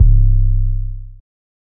808 (JUNGLE C).wav